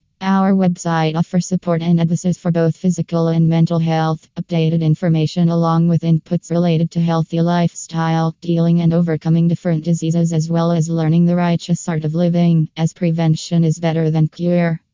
speech-health.wav